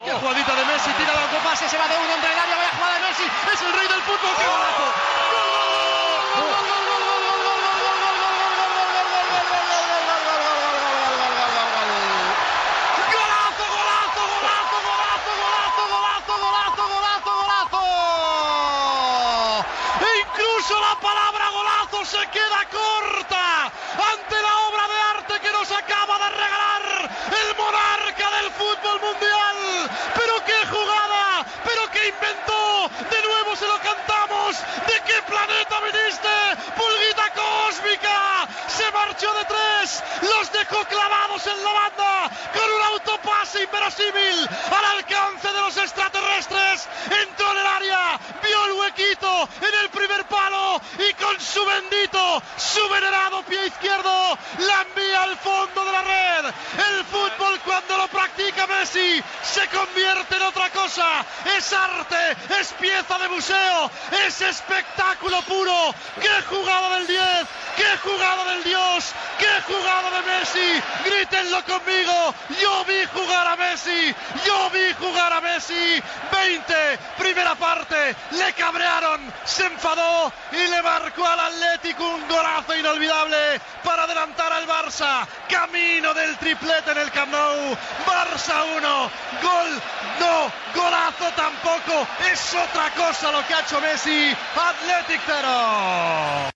Narració dels del Camp Nou de Barcelona del gol de Leo Messi a la final de la Copa del Rei de futbol masculí entre el Futbol Club Barcelona i l'Athletic Club (El partit va acabar 3 a 1 a favor del Barça)
Esportiu